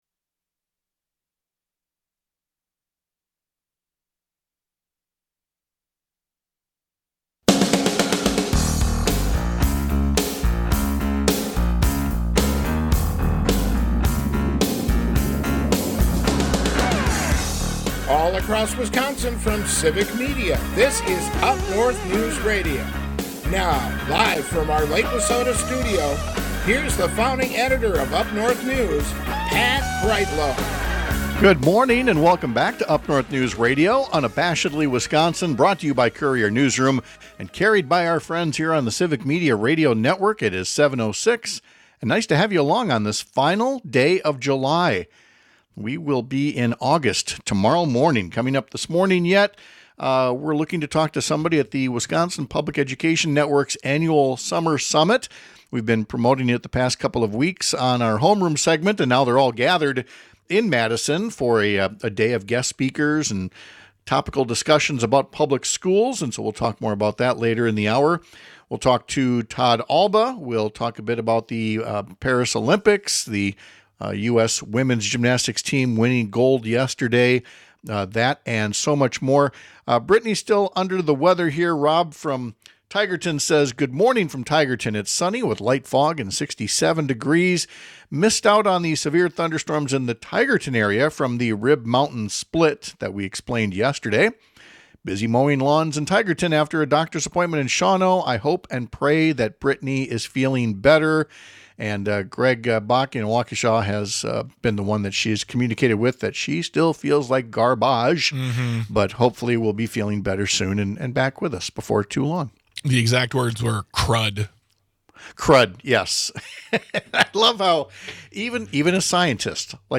We’ll go live to the Wisconsin Public Education Network’s annual Summer Summit of guest speakers and topical discussions. And we’ll discuss that big gold medal win for the US gymnasts at the Paris summer Olympic games.